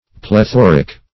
Plethoric \Ple*thor"ic\, a. [Gr.